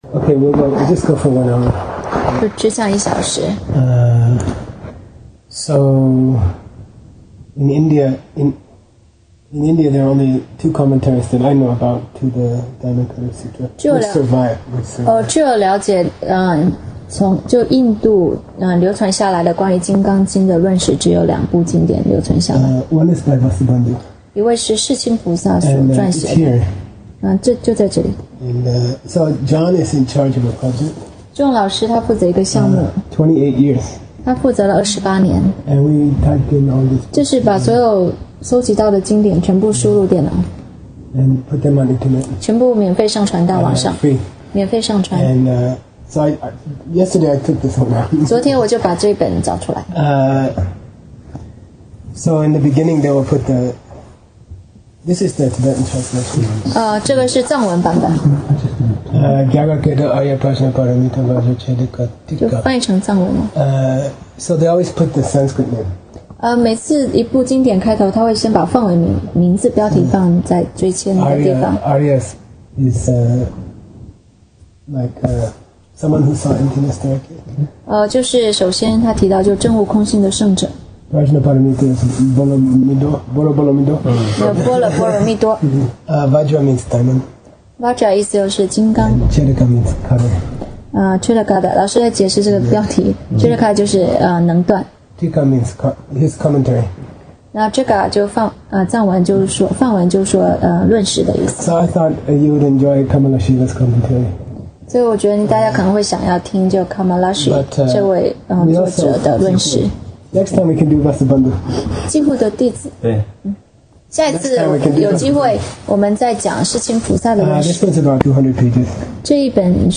While at the top and in the teaching hall of this temple